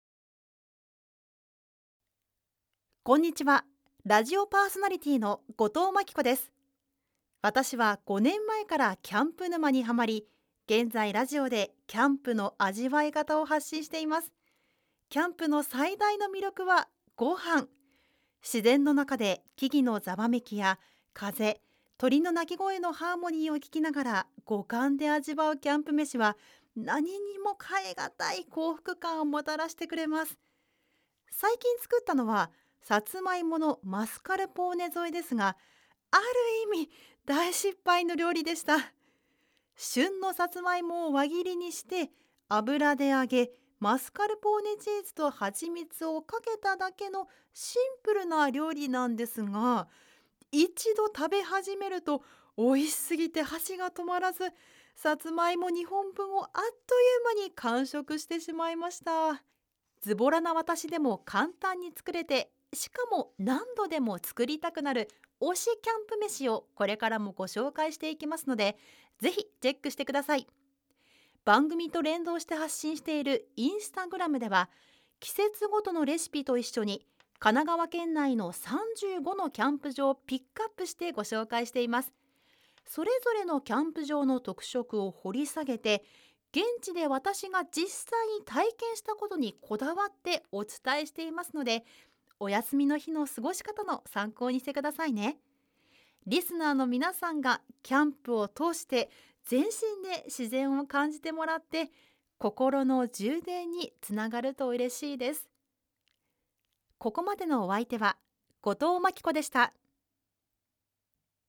ボイスサンプル
自己紹介
自己紹介new.mp3